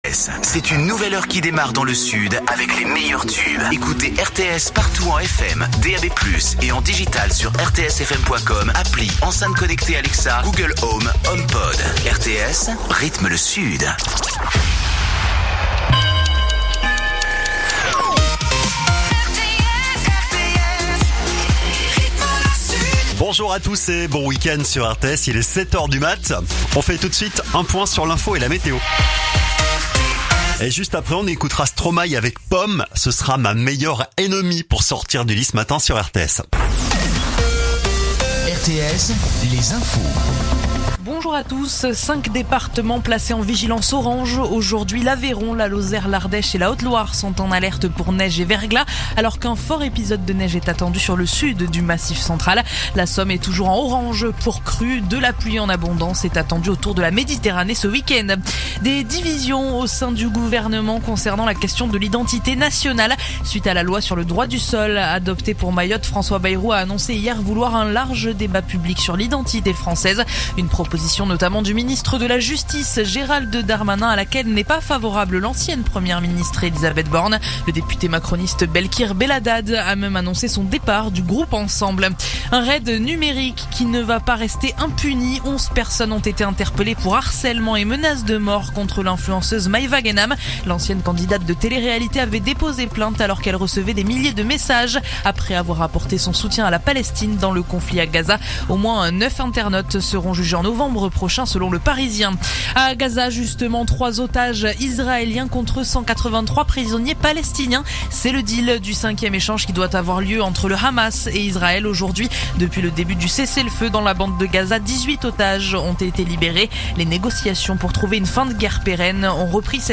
info_perpignan_288.mp3